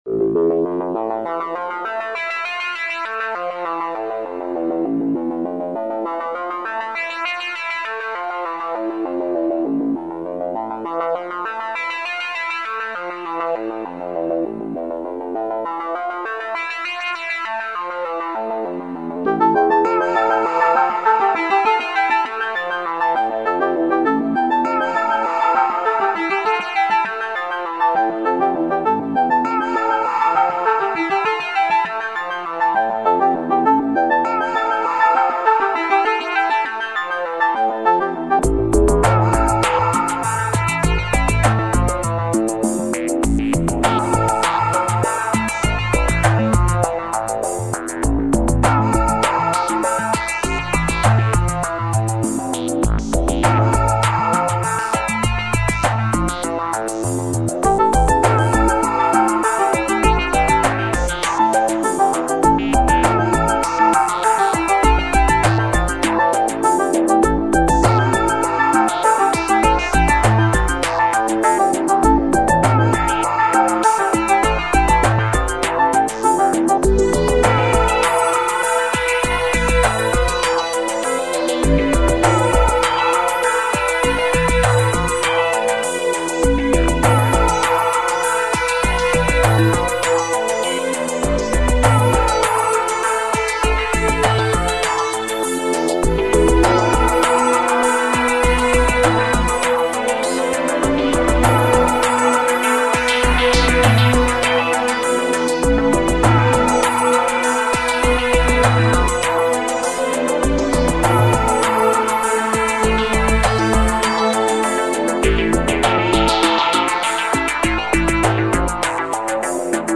电子味道很香...